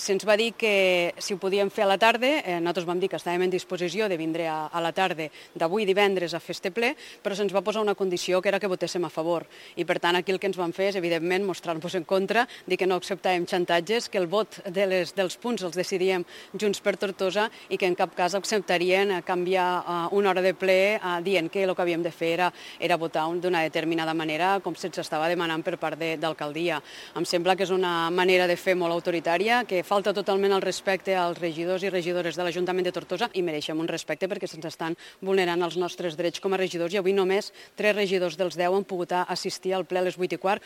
La portaveu del grup, Meritixell Roigé, ha acusat el govern municipal d’actuar de forma autoritària en la convocatòria dels plens i de vulnerar els drets dels regidors…